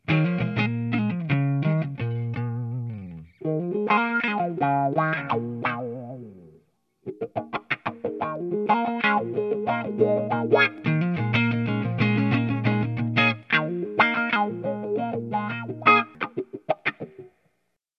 Je vous remets le premier aussi en clean
Chase-Tone-Gypsy-Wah-Clean-Marshall.mp3